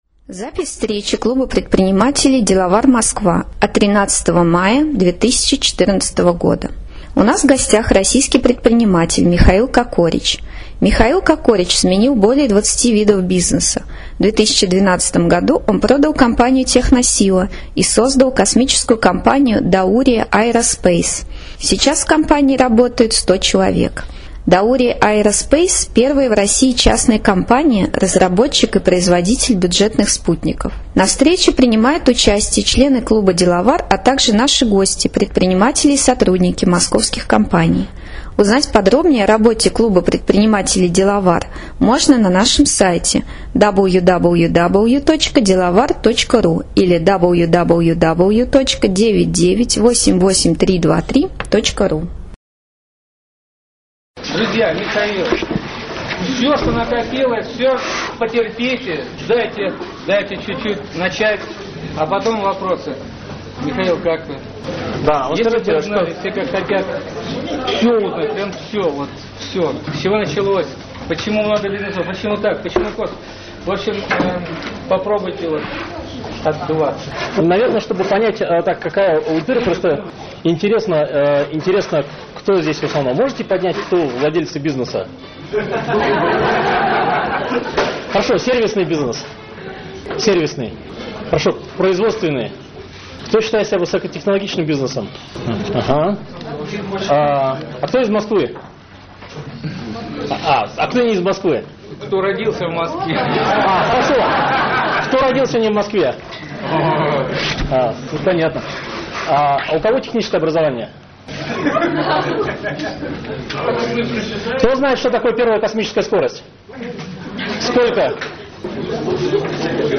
13 мая 2014 года прошла очередная встреча клуба предпринимателей Деловар в Москве.